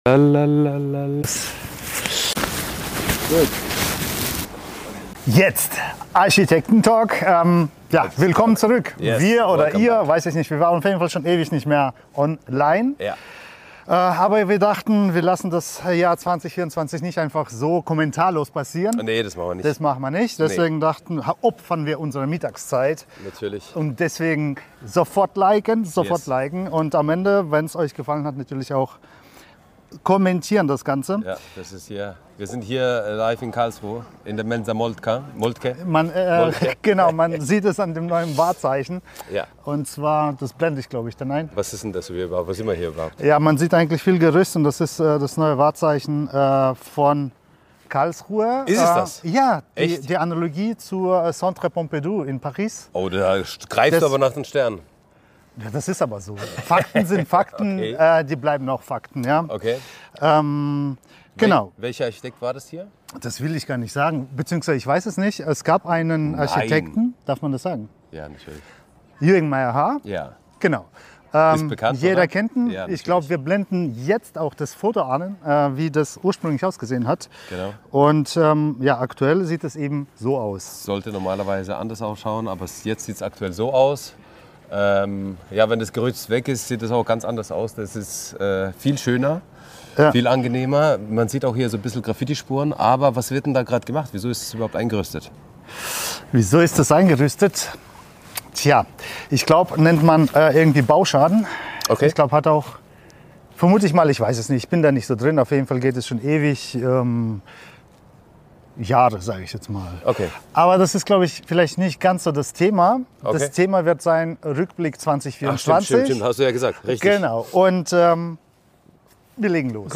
In diesem Video werfen wir, zwei Architekten, einen detaillierten Blick zurück auf das Jahr 2024. Gemeinsam sprechen wir über die Herausforderungen, Entwicklungen und spannenden Wendepunkte, die die Baubranche in diesem Jahr geprägt haben.